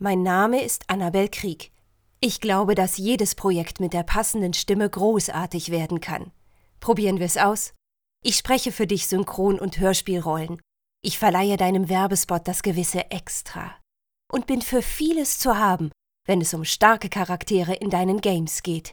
sehr variabel, hell, fein, zart, markant
Mittel minus (25-45)
Hessisch, Norddeutsch
Eigene Sprecherkabine